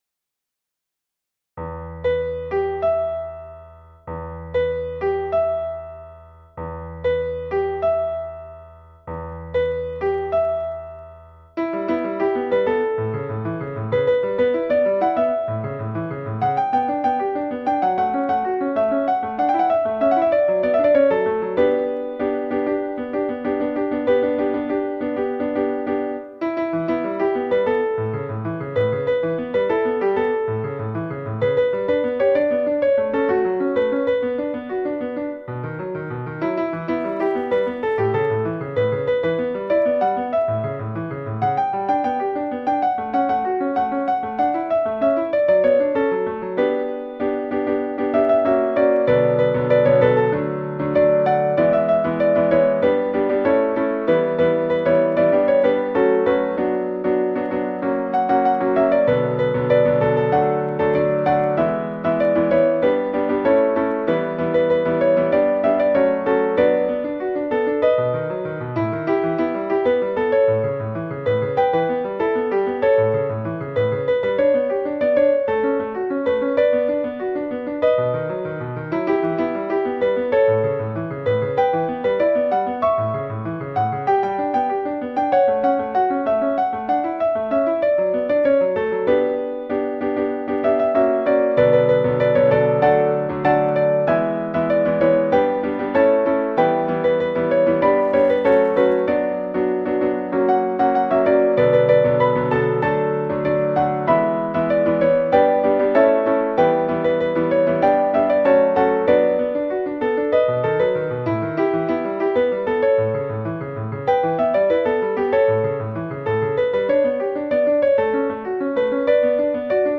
• Теги: Ноты для фортепиано
Ноты для фортепиано.